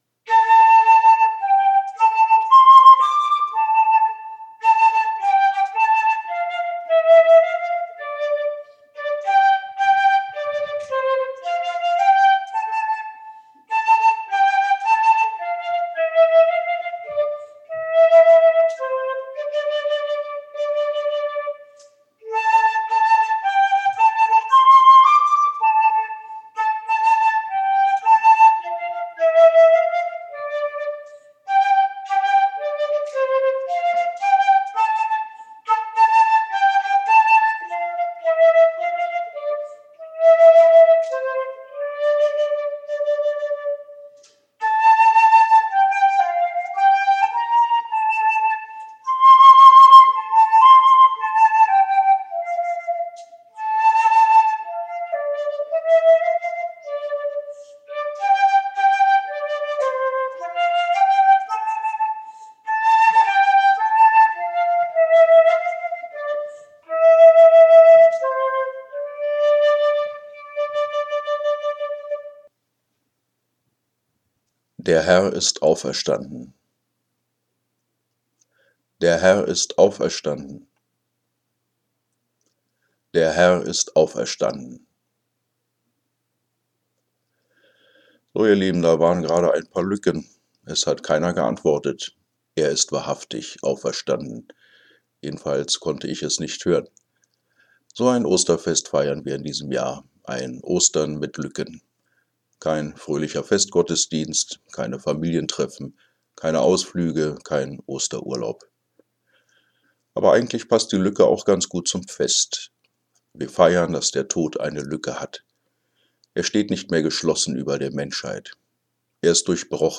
Flöte
Piano